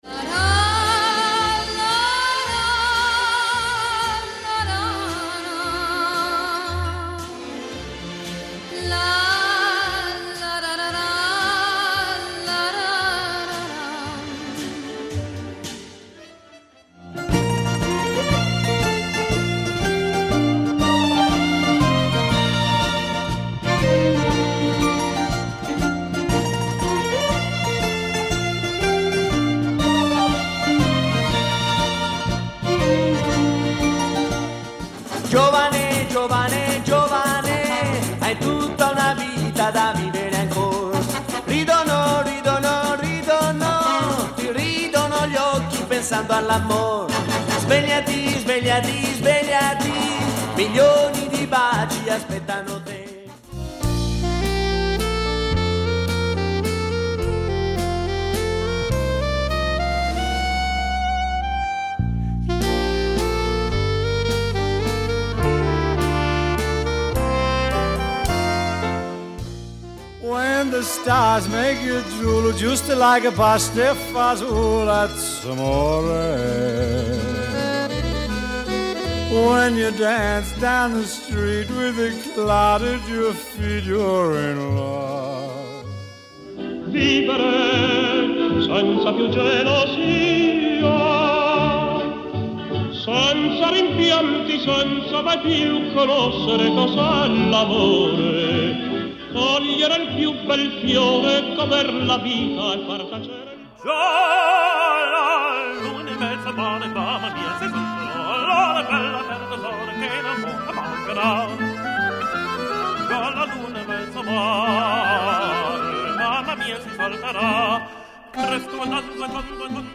Italian Traditional
Description: Traditional Italian Vocals and Instrumentals.